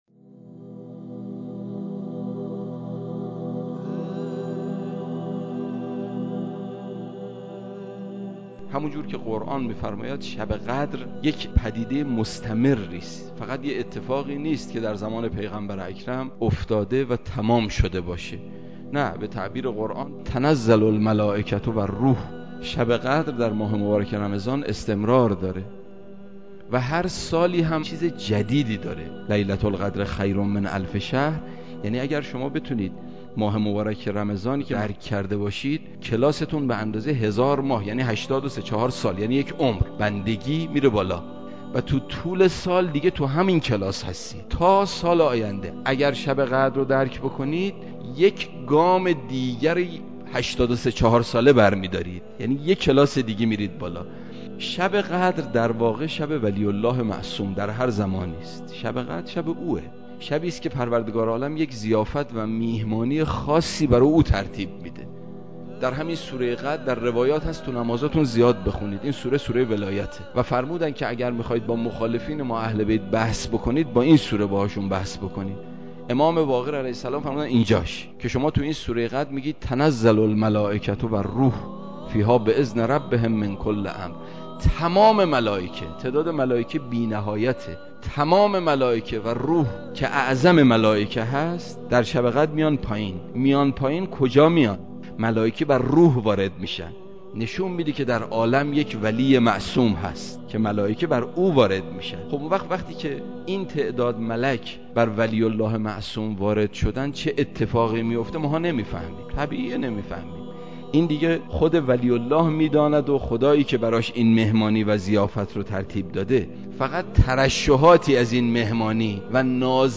در حرم مطهر رضوی